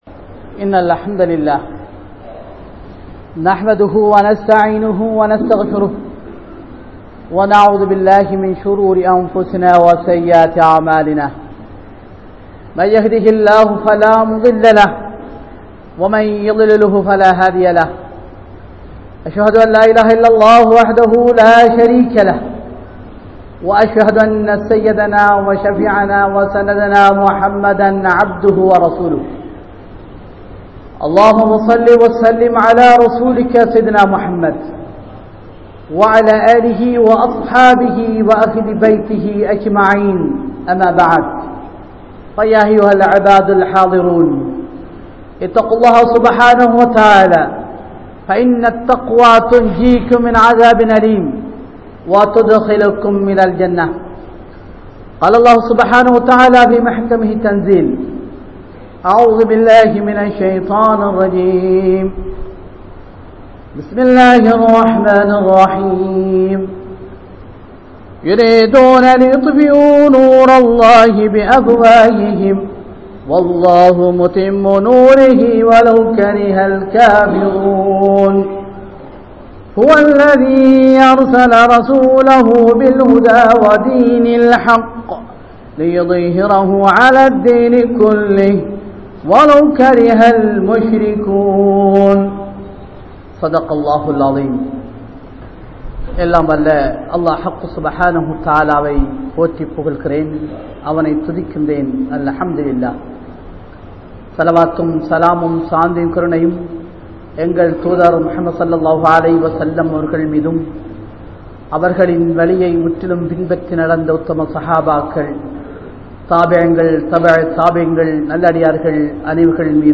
Samahaala Muslimkalin Pirach`chinaihal(சமகால முஸ்லிம்களின் பிரச்சினைகள்) | Audio Bayans | All Ceylon Muslim Youth Community | Addalaichenai